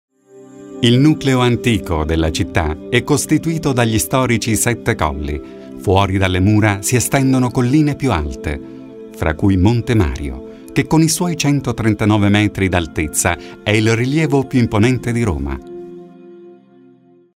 Sprecher italienisch.
Sprechprobe: Industrie (Muttersprache):
Corporate Image 1.mp3